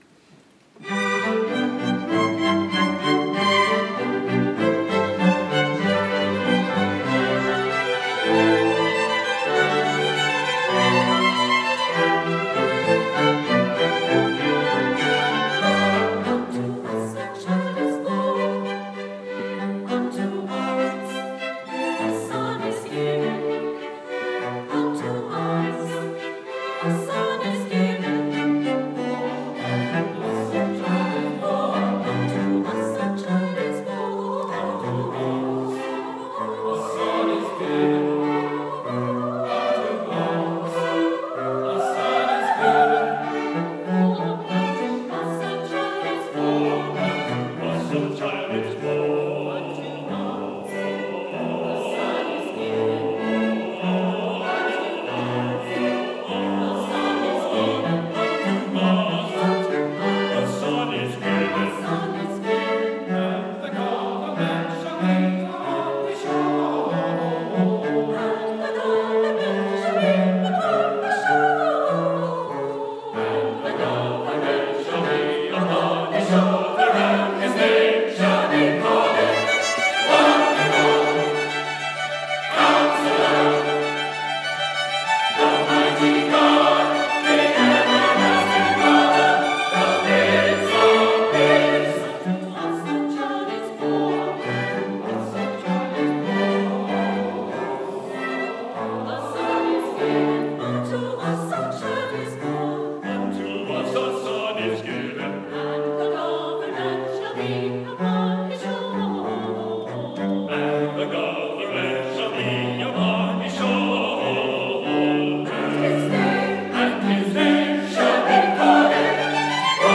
December 13, 2015 Concert